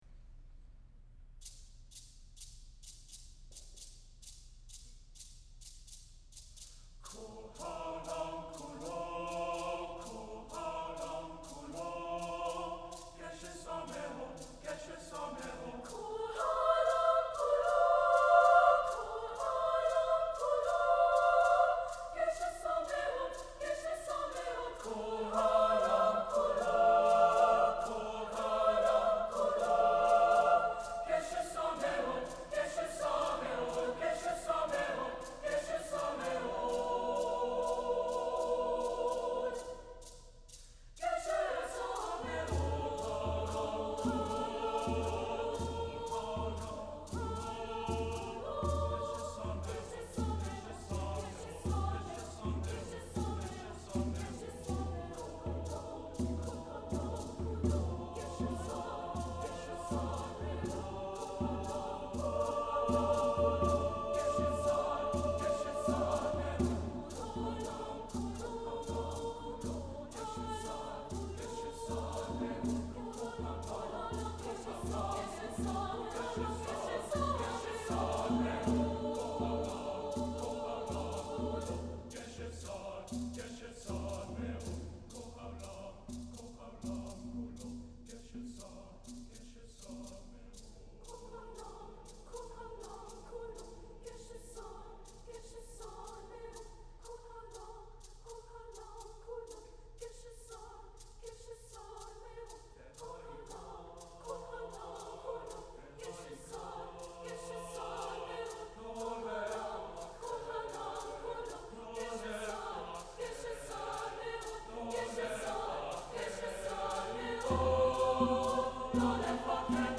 SATB, percussion(2)
Set in the original Hebrew, with hand percussion.